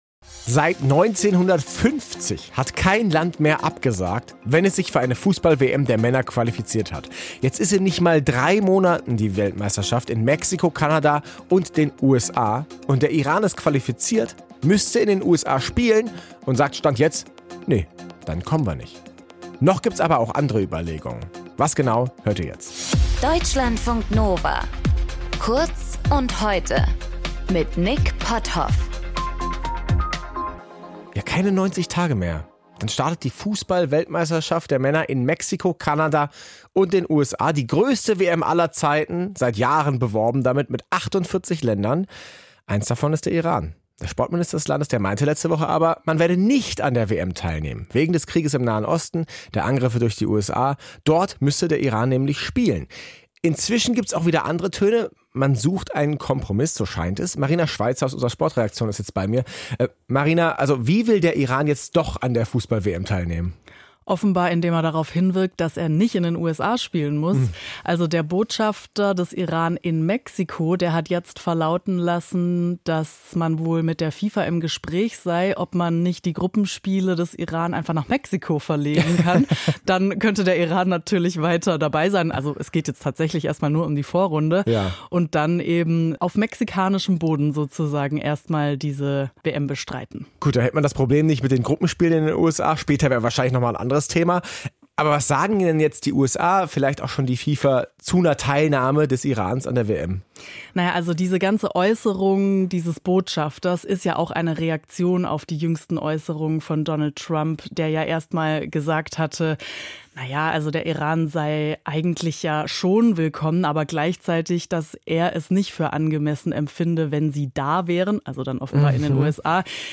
Moderation
Gesprächspaartnerin